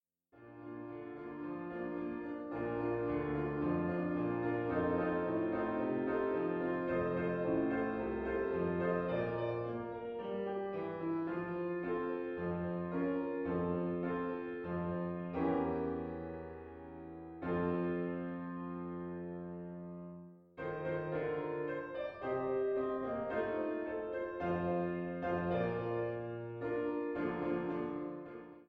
Arie
Klavier-Sound